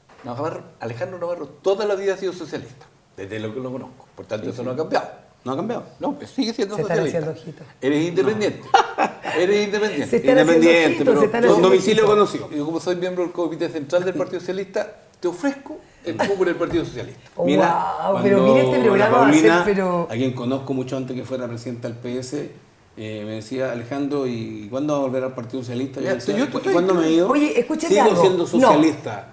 La información la entregó el excandidato a gobernador en el programa El Tablón de Canal 9 Bío Bío Televisión, en medio de una conversación política donde también participó la diputada Marlén Pérez y Gastón Saavedra.
Las reacciones de asombro en la mesa, no se dejaron esperar y Gastón Saavedra, senador del PS, le propuso entre risas el cupo en su candidatura por el partido, donde anteriormente militaba Navarro, antes de formar el Más y actualmente ser independiente.